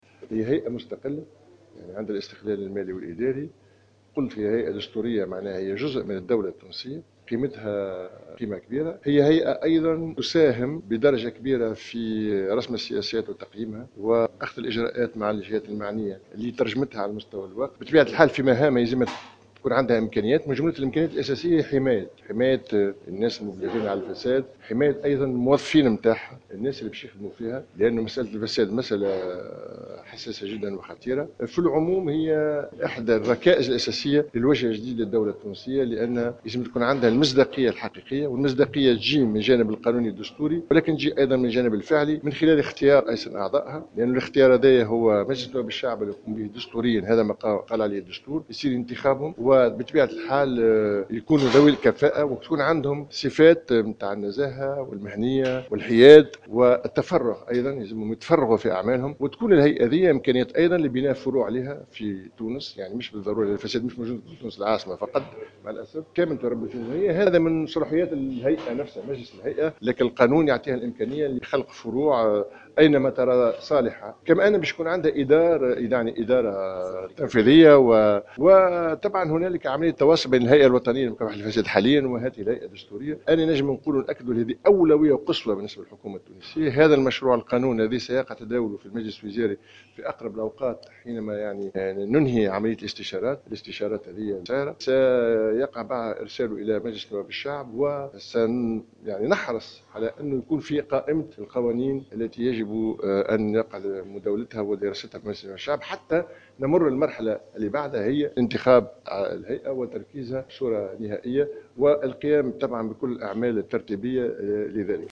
قال وزير العلاقة مع الهيئات الدستورية والمجتمع المدني وحقوق الإنسان كمال الجندوبي في تصريح للجوهرة ف أم اليوم الجمعة 27 ماي 2016 إن هيئة الحوكمة الرشيدة ومكافحة الفساد هي إحدى الركائز الأساسية للوجه الجديد للدولة التونسية باعتبارها ستساهم في رسم السياسات وتقييمها.